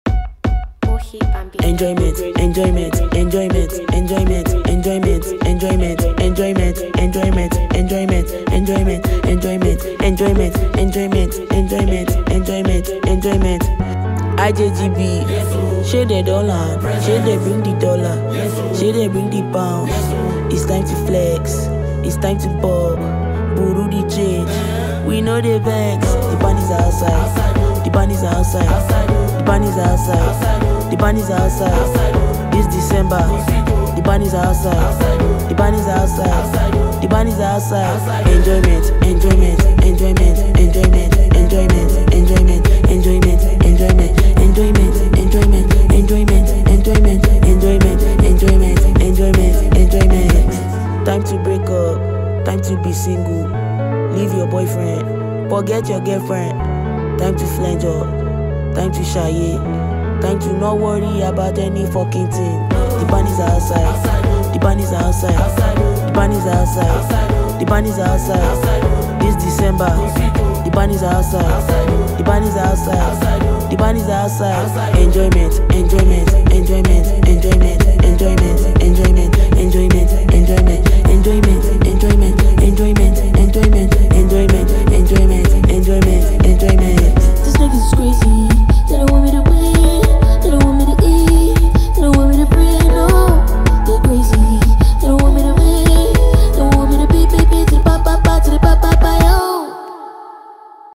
Esteemed Nigerian female singer and performer